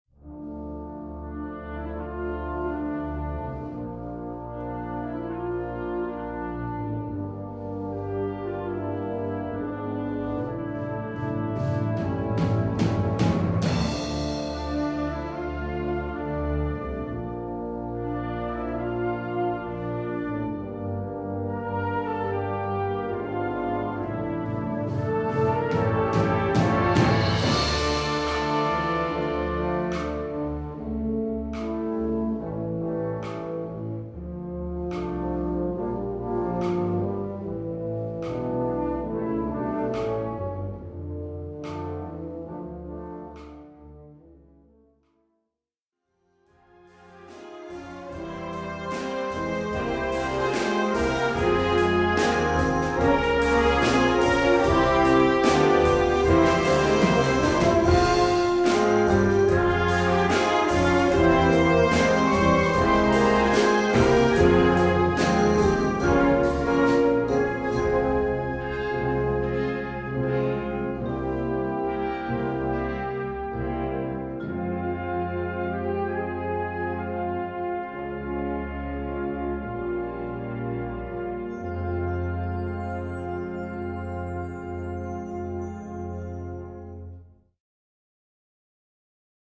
Besetzung: Blasorchester
wohlklingenden Choral